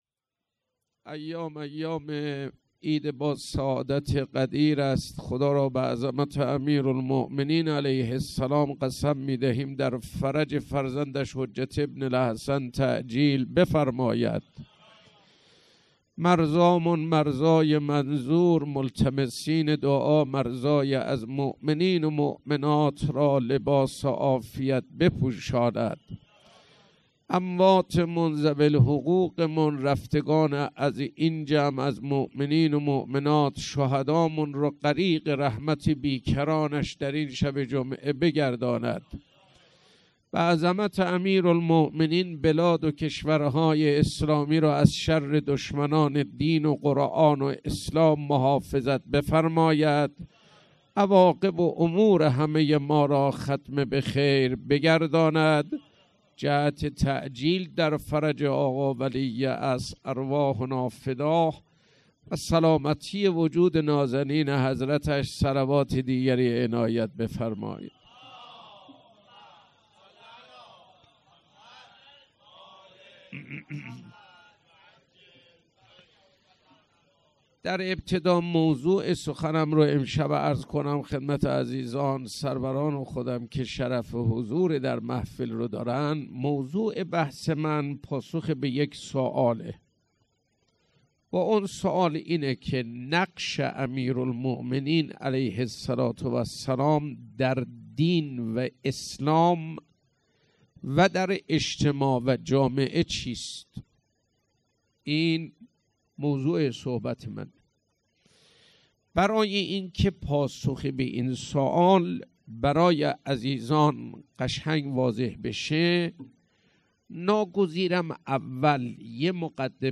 مراسم عید غدیر ۱۴۰۴